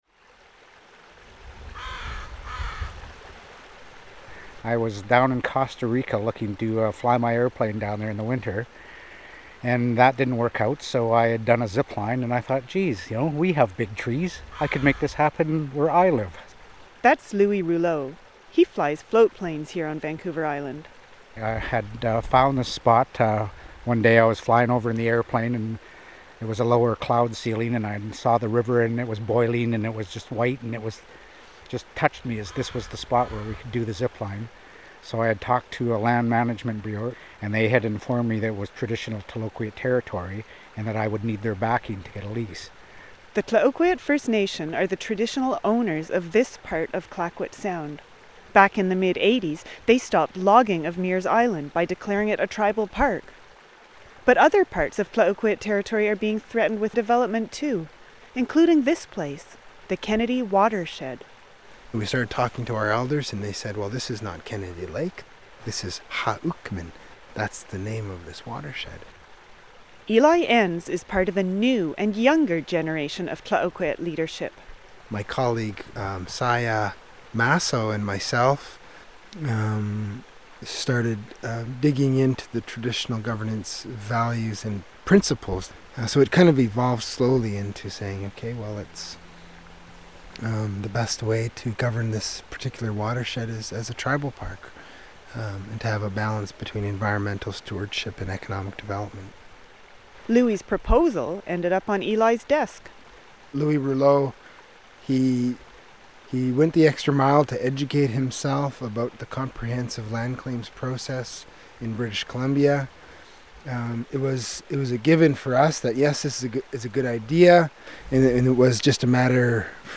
I have written, recorded and produced numerous documentaries as a freelance broadcaster, for Canada’s national broadcaster CBC Radio,  as well as for other radio networks nationally and internationally.